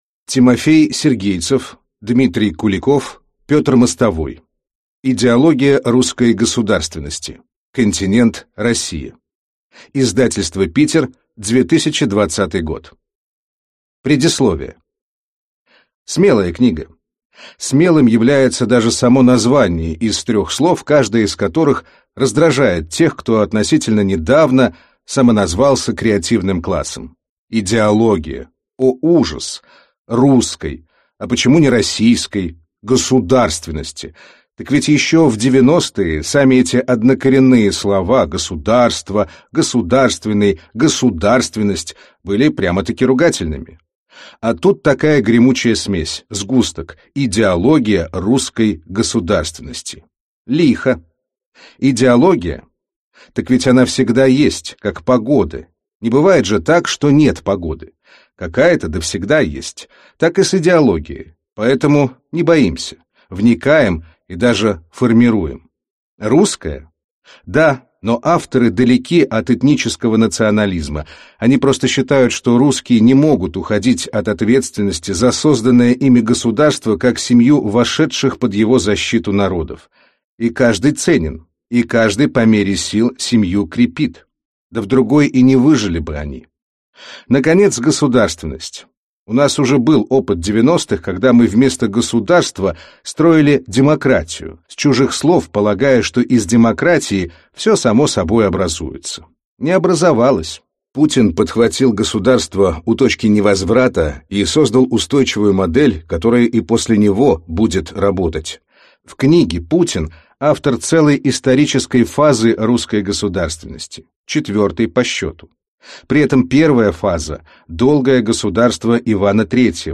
Аудиокнига Идеология русской государственности. Континент Россия (часть 1) | Библиотека аудиокниг